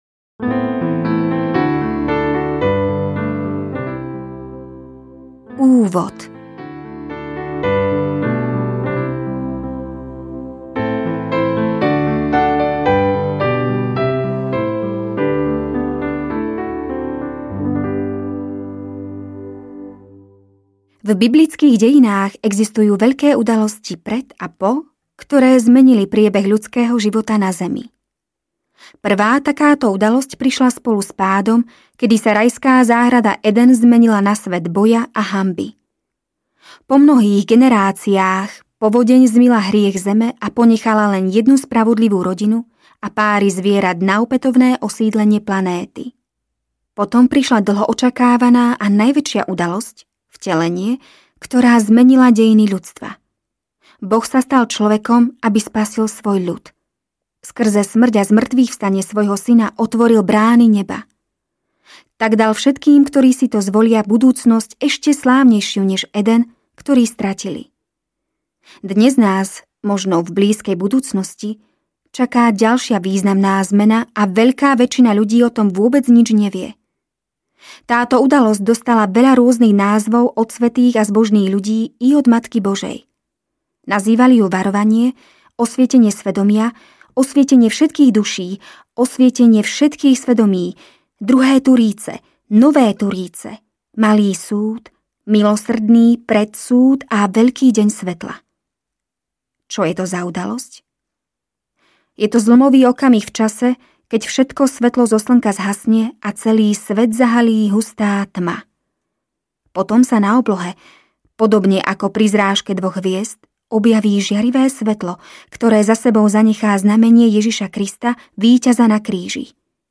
Varovanie audiokniha
Ukázka z knihy